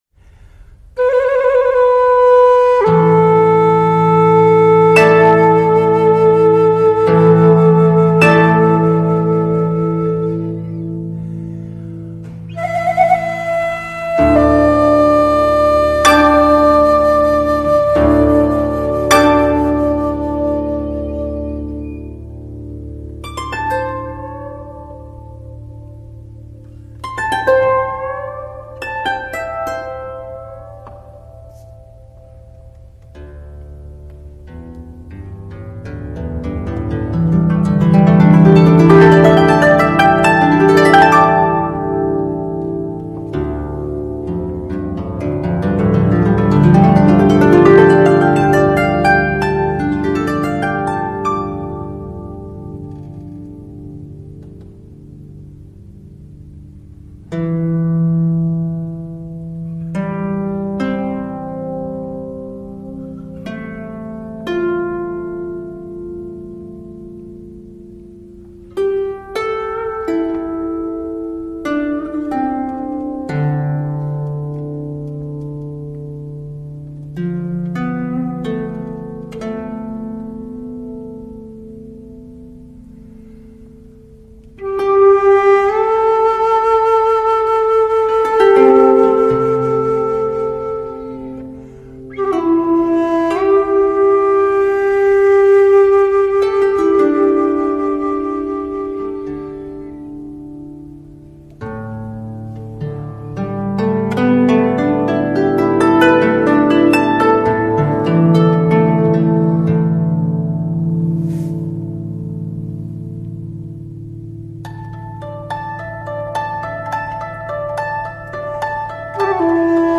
箫与箜篌二重奏
（低品质）